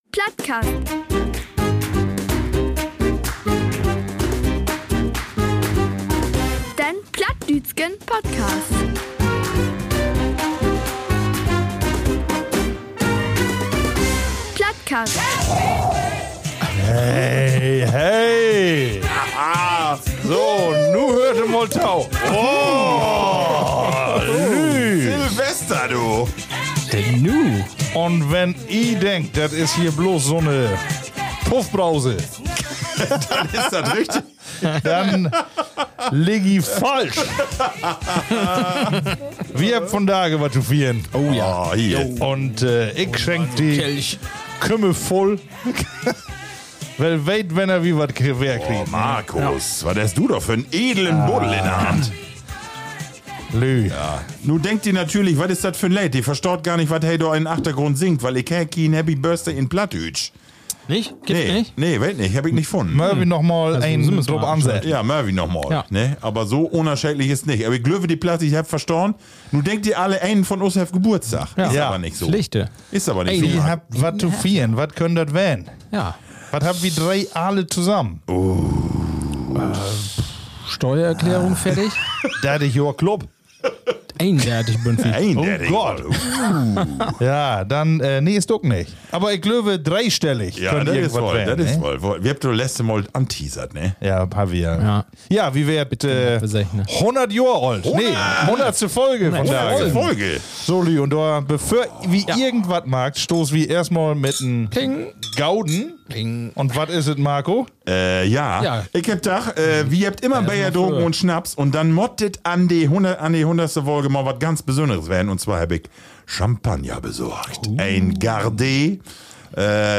Humorvoll.
Und weil Folge 100 nicht einfach nur ’ne Zahl ist, kommen heute auch die Plattis selbst zu Wort und gratulieren. Und gefeiert wird standesgemäß: Zu Beginn gibt’s anstatt Bier – man höre und staune – zum allerersten Mal Champagner: Champagne Gardet aus Frankreich.